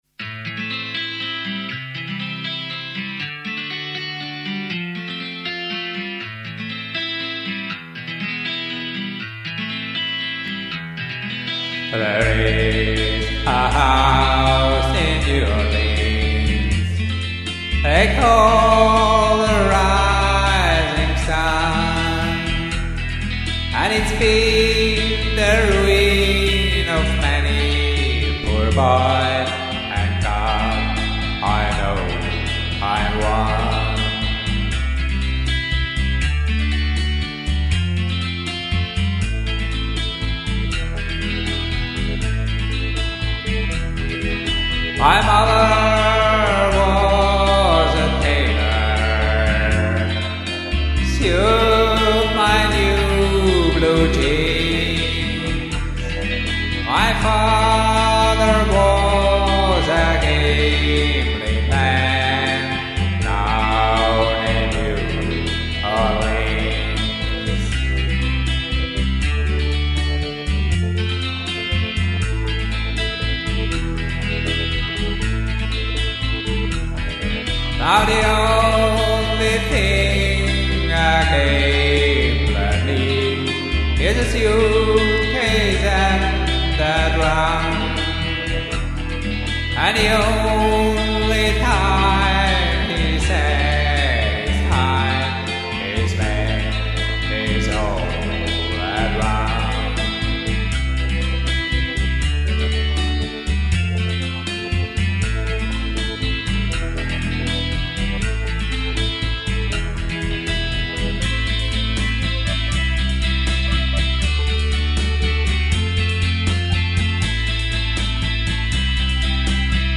Karaoke MP3 Version